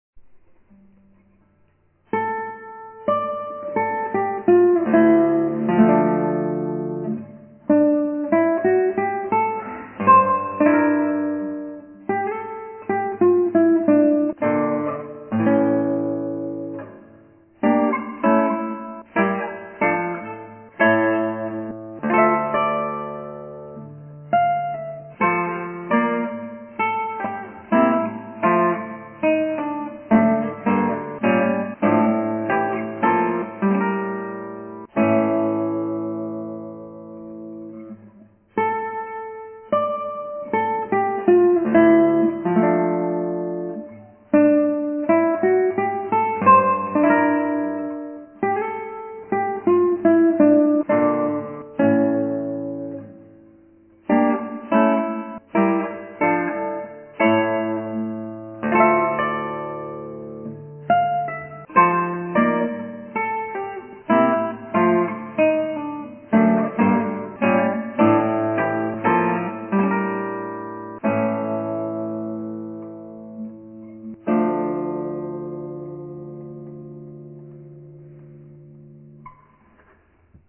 ギターはアントニオマリン